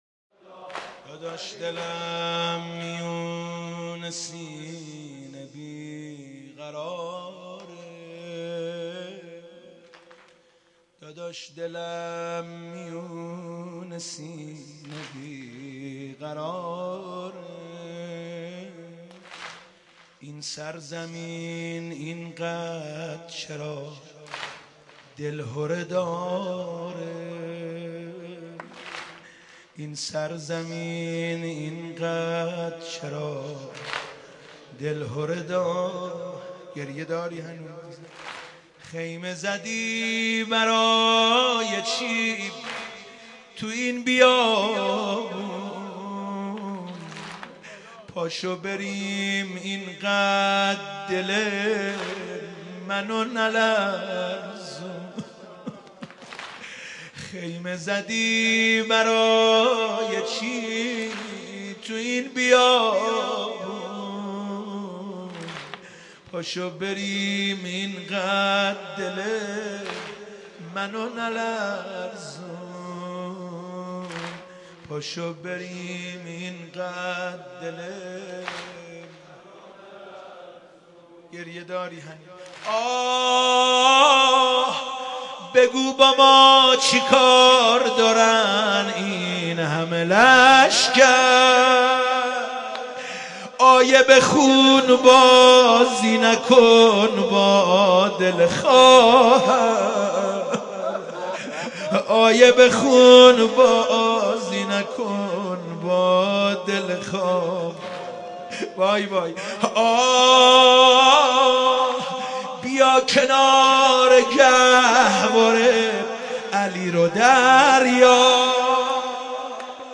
مداحی جدید
شب دوم محرم97 تهران مسجد امیر